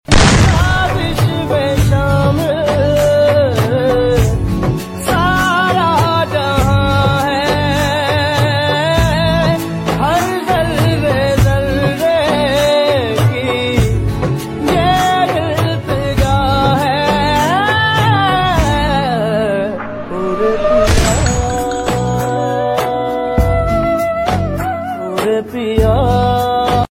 Sad Vibes